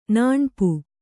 ♪ nāṇpu